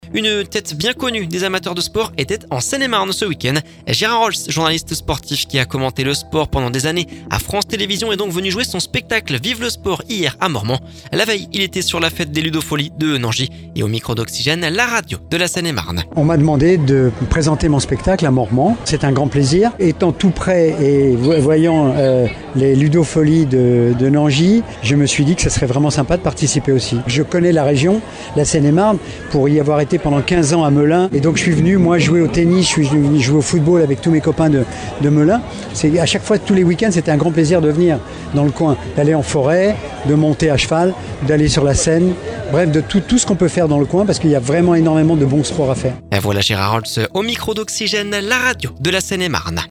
Et au micro Oxygène, la radio de la Seine-et-Marne.